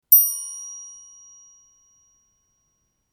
newmsg.mp3